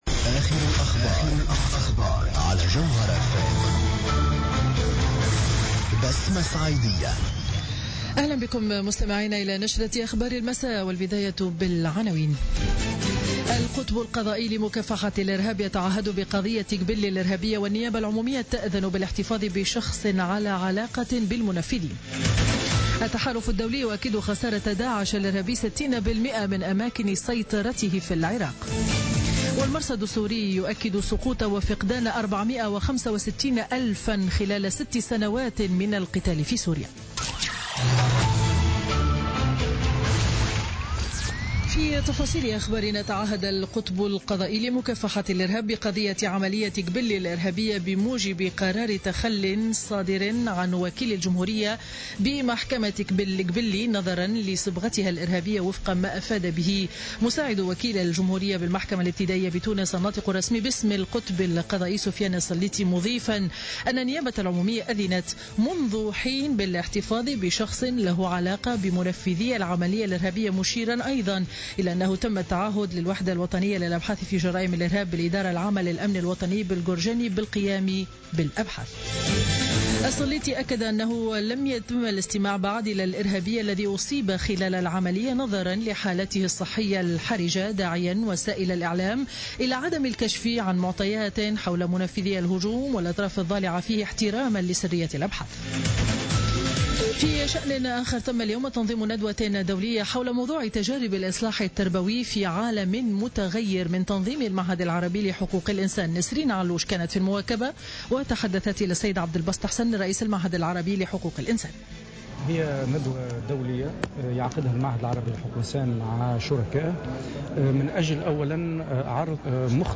نشرة أخبار السابعة مساء ليوم الاثنين 13 مارس 2017